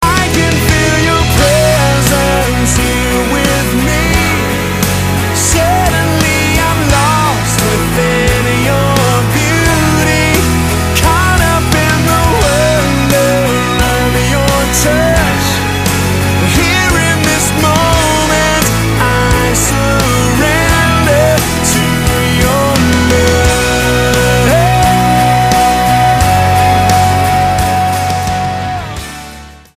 STYLE: Rock
magnificent string arrangements
haunting mid tempo